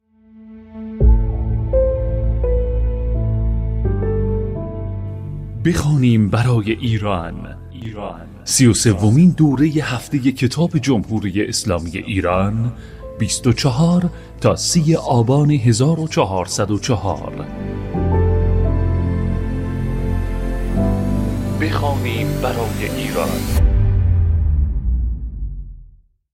دریافت آنونس